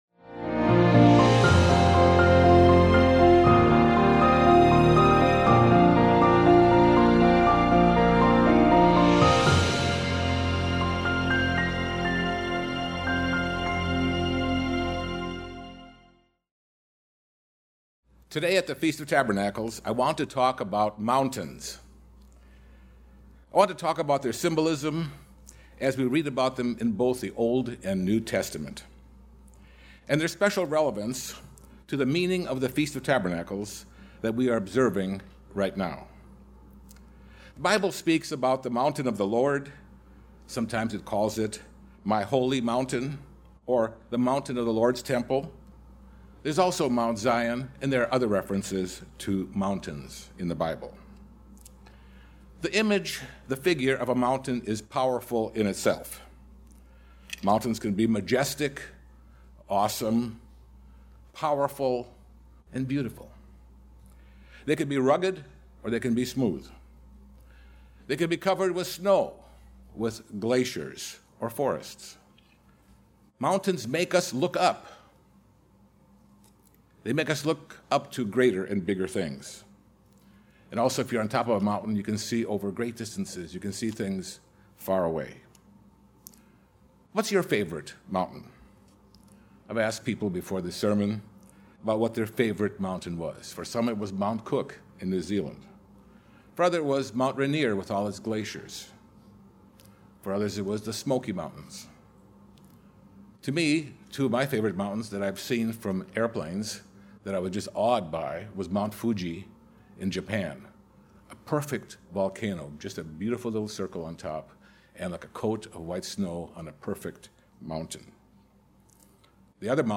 This sermon was given at the Panama City Beach, Florida 2019 Feast site.